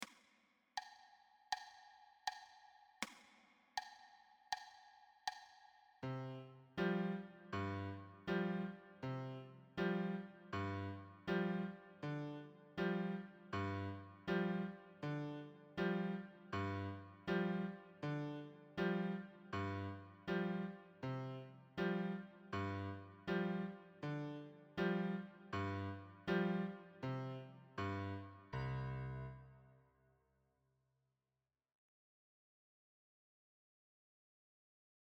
Lehrerbegleitung